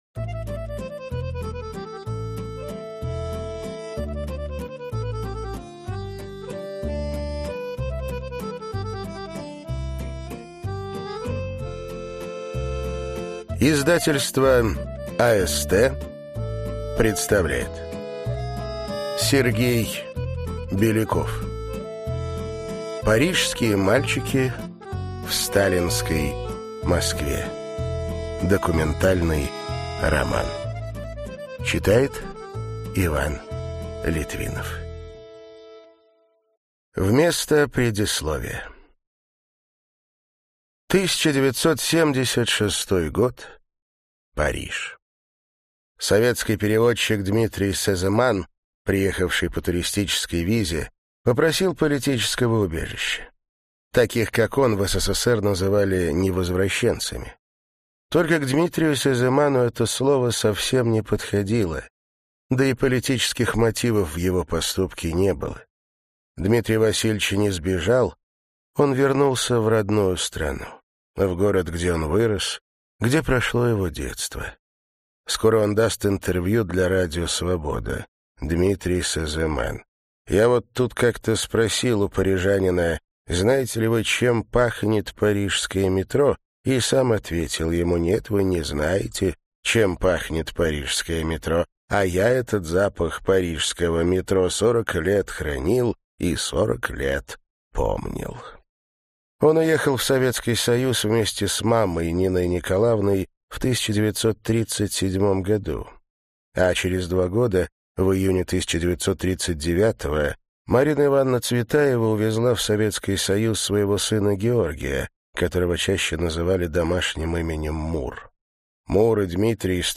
Аудиокнига Парижские мальчики в сталинской Москве | Библиотека аудиокниг